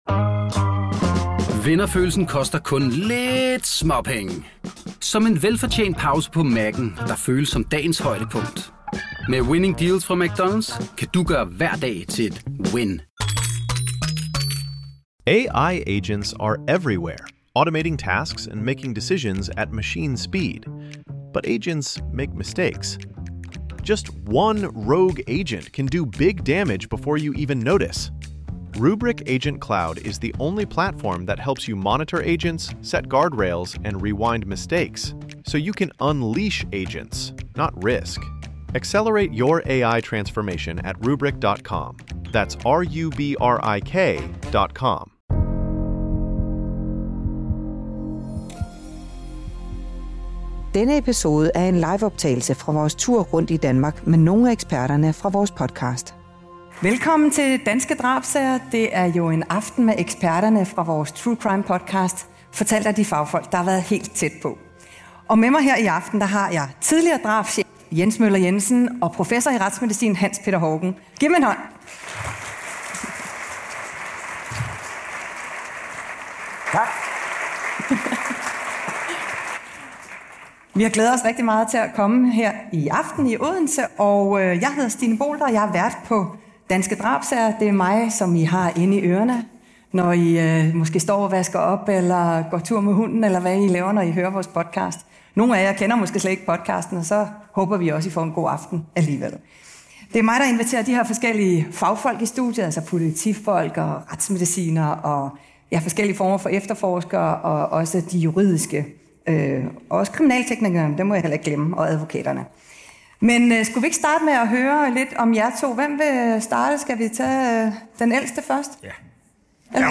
Det går vi tæt på i denne episode af Danske Drabssager, som er optaget live på scenen i Magasinet i Odense den 30. januar 2024. Vi ser nærmere på politiets efterforskning, de kriminaltekniske undersøgelser og ikke mindst de meget vigtige retsmedicinske erklæringer.